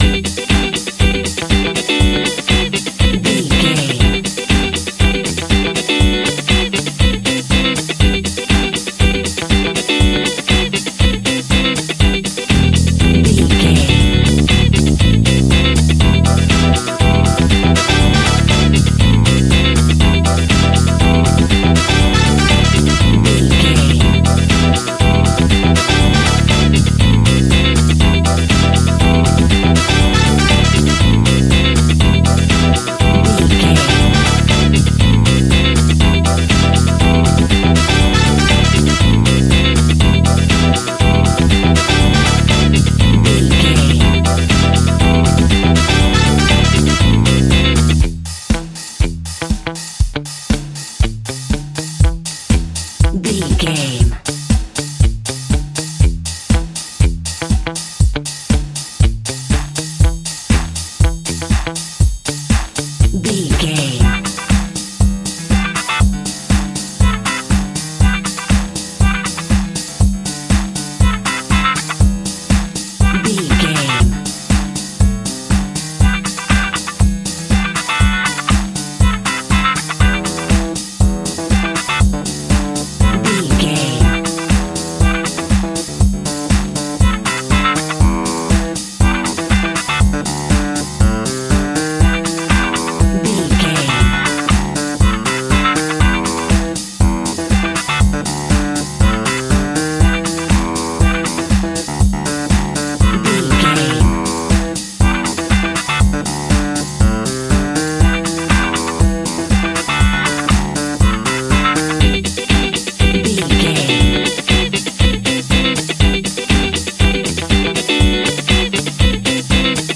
Aeolian/Minor
groovy
uplifting
energetic
drums
bass guitar
electric piano
synthesiser
electric guitar
brass
disco house
electronic funk
upbeat
synth leads
Synth Pads
synth bass
drum machines